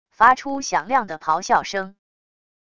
发出响亮的咆哮声wav音频